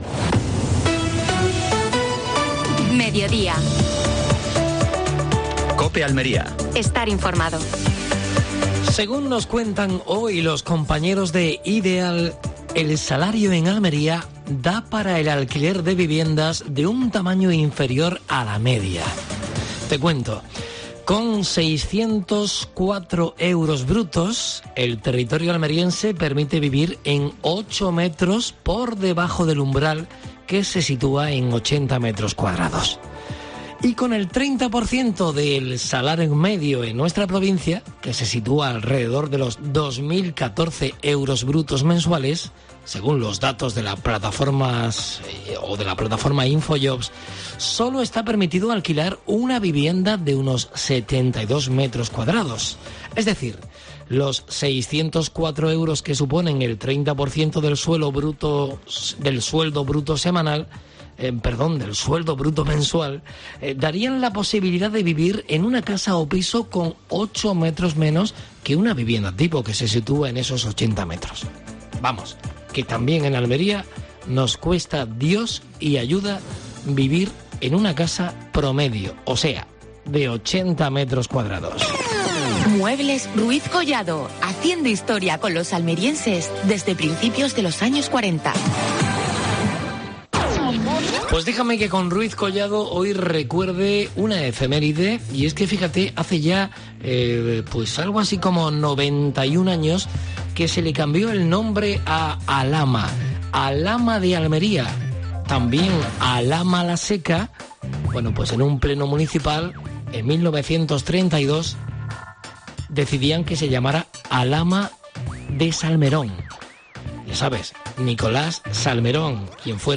AUDIO: Actualidad en Almería. Desde Vera, entrevista a Antonia Marín (concejal de Festejos).